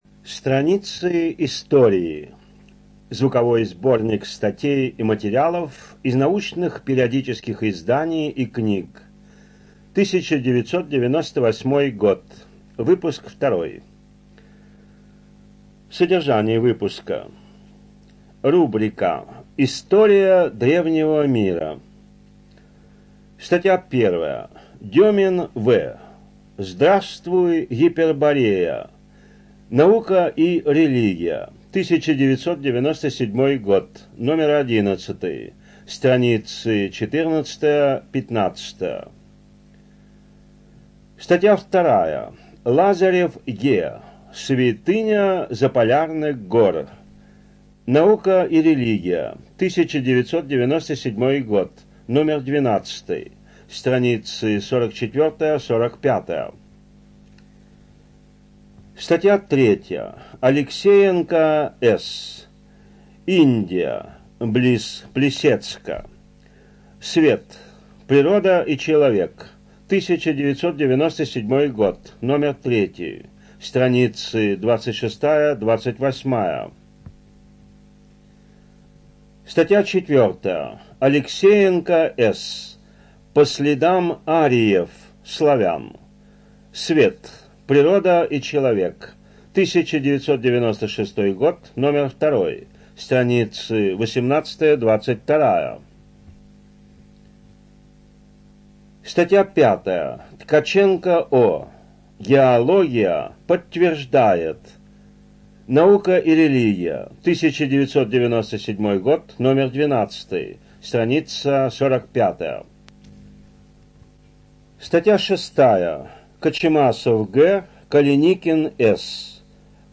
Студия звукозаписиРоссийская государственная библиотека для слепых